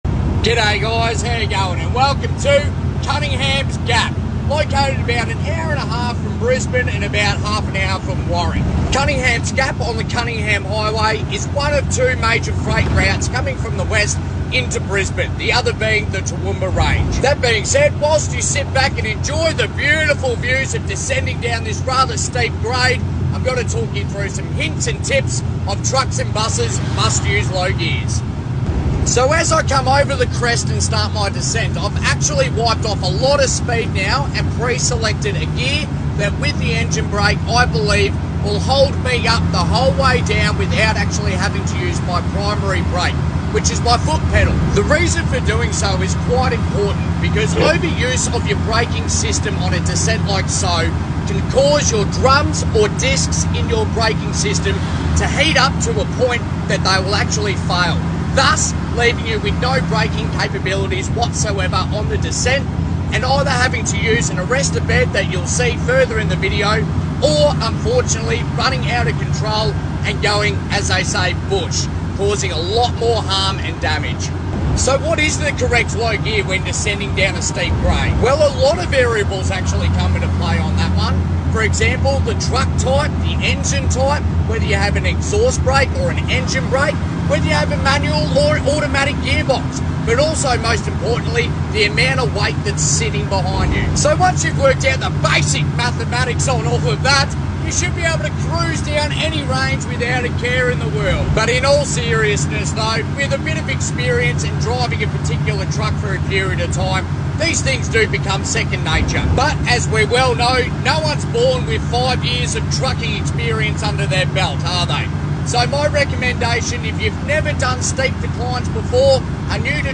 Big Truck - Big Hill - Engine Braking Aussie Style